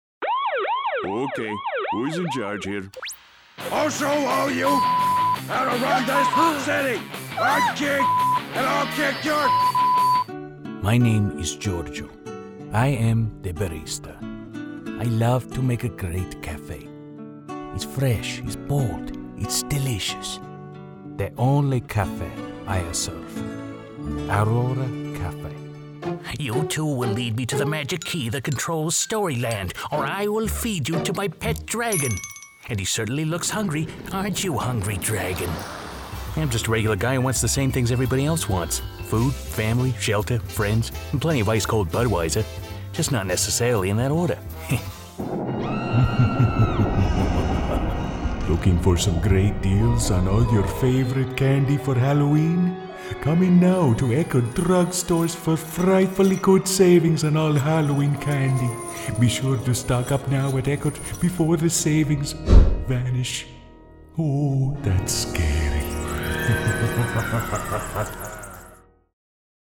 -male non-union voice talent -resonant mid-range to bass -clear, warm and friendly -good dramatic range & comedic timing -ideally suited for narration, corporate, commercial work and audio books
Sprechprobe: Sonstiges (Muttersprache):